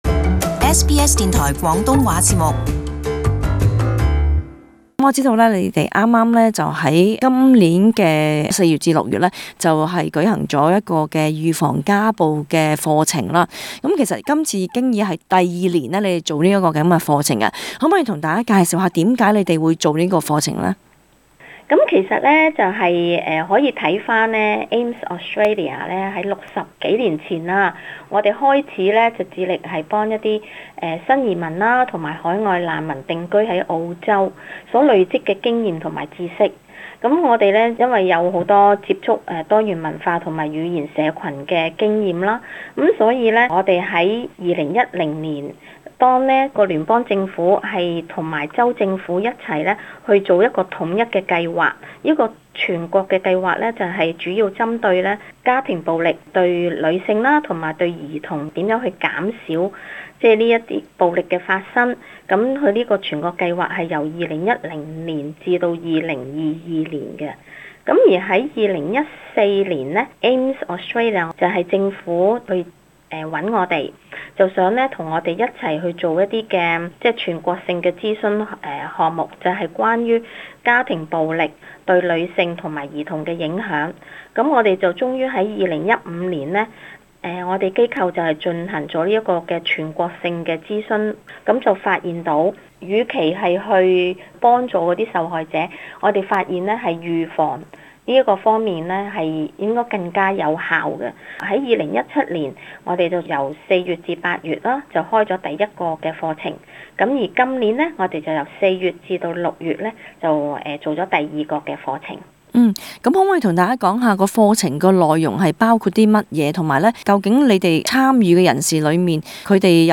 【社區專訪】AMES推出首個針對青年防止家暴課程